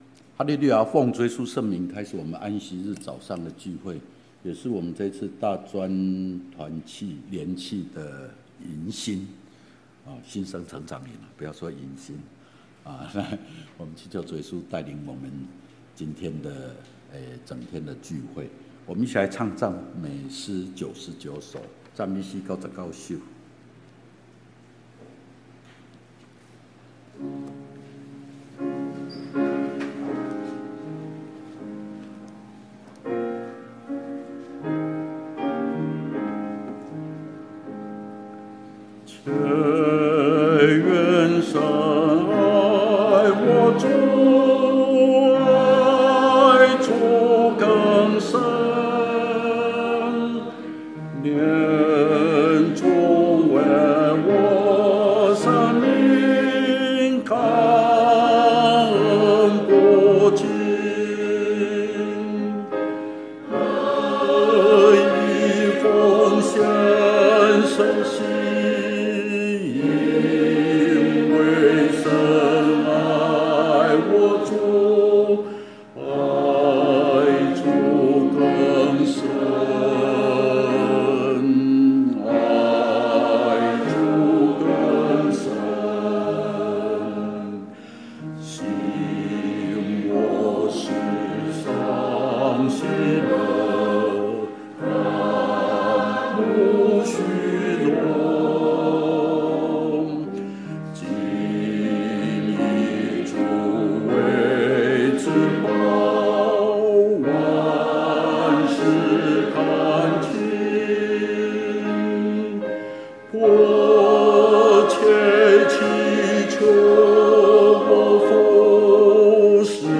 *111學年度北區大專新生成長營